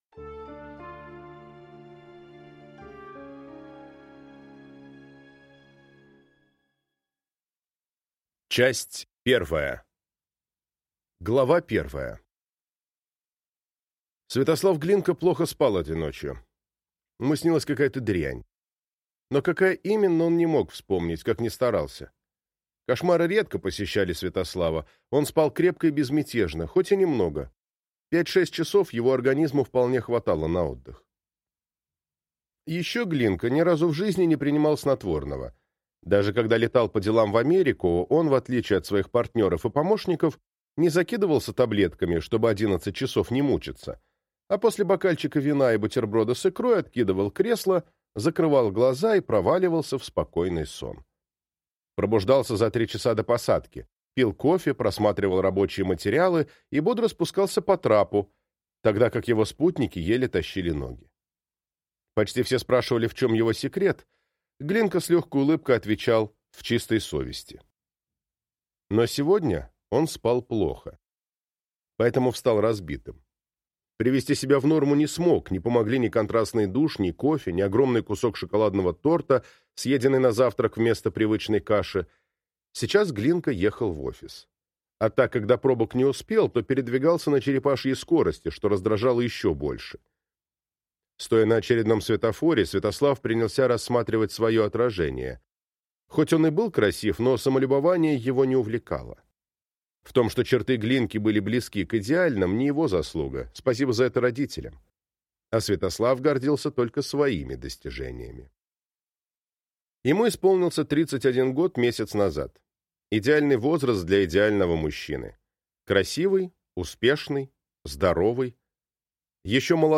Аудиокнига Поединок с мечтой | Библиотека аудиокниг